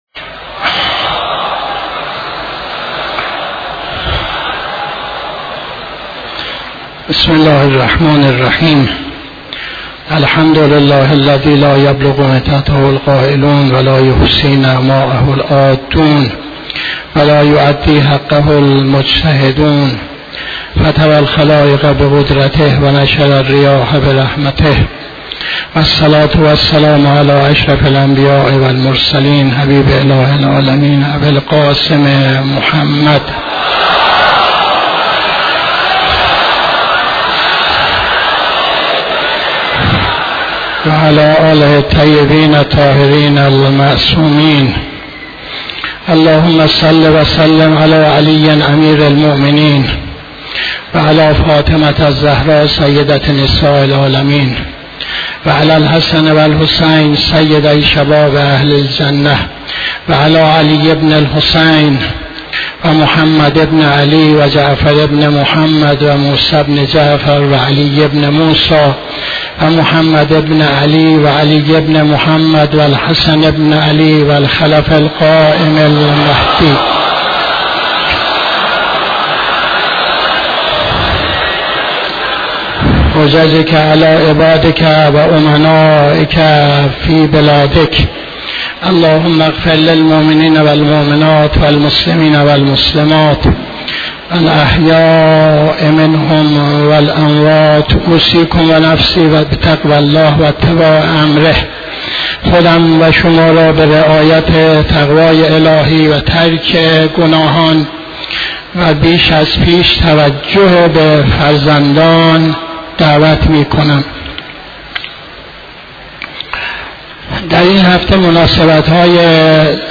خطبه دوم نماز جمعه 08-03-83